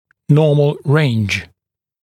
[‘nɔːml reɪnʤ][‘но:мл рэйндж]нормальный диапазон, нормальные пределы